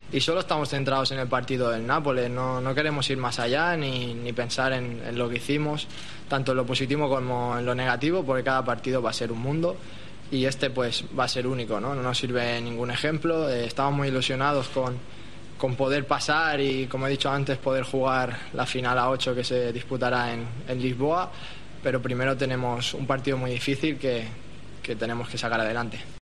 AUDIO: El segundo capitán del Barcelona atendió a los medios en la previa del partido de este sábado frente al Nápoles.